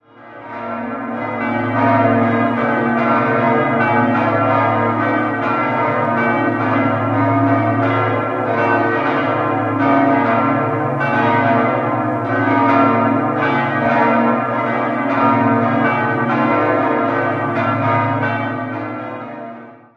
4-stimmiges Hauptgeläute: b°-c'-d'-es'
bell
Bonn_Muenster.mp3